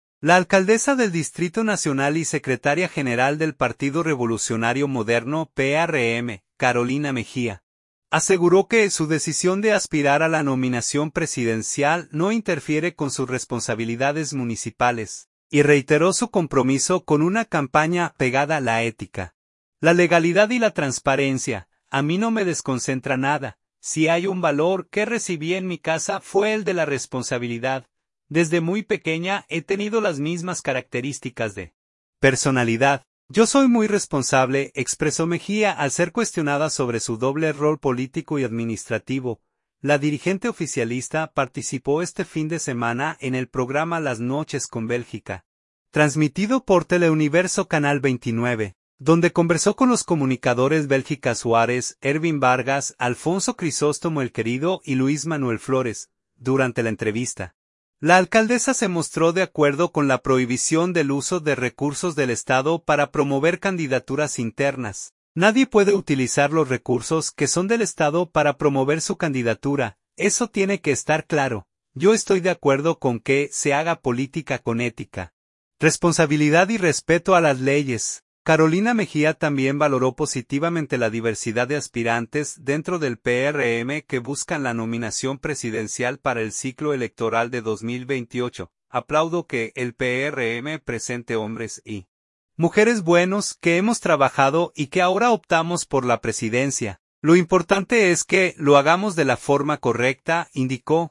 Durante la entrevista, la alcaldesa se mostró de acuerdo con la prohibición del uso de recursos del Estado para promover candidaturas internas.